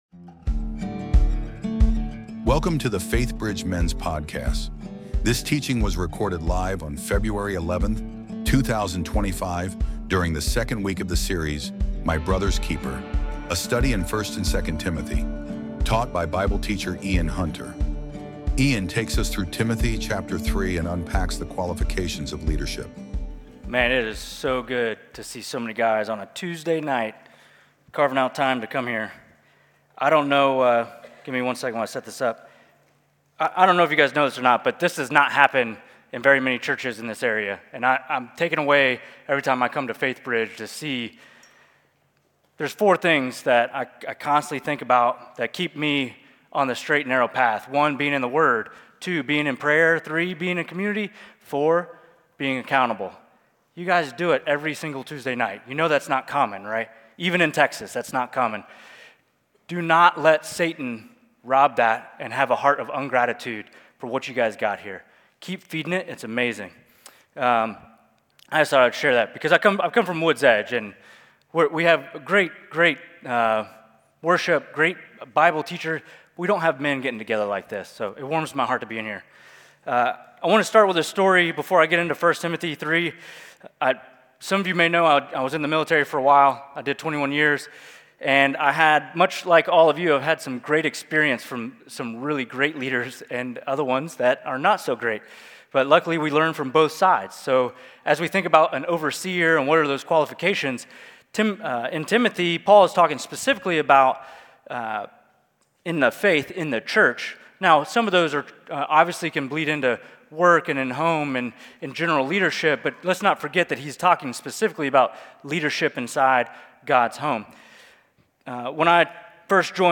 Week 2 of "My Brother's Keeper" Series, a study of 1 and 2 Timothy. Taught by Bible Teacher